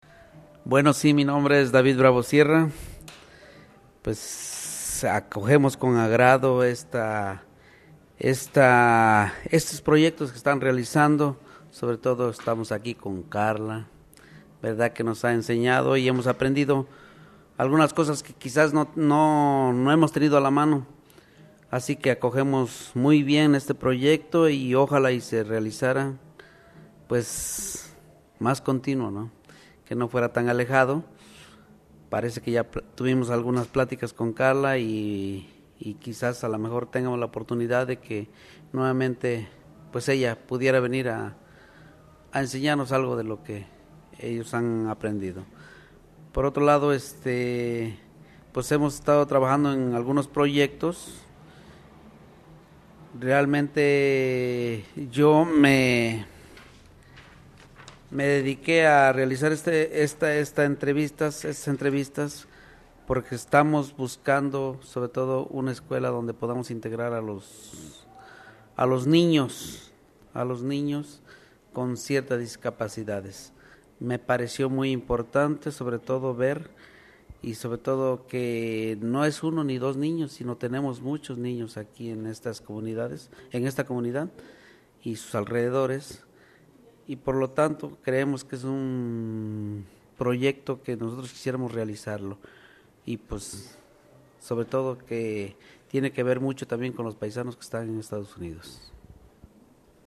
The interview lasted a little more than an hour.
Everybody spoke very clearly, calmly, and with good volume, as if they had done it before.
interview.mp3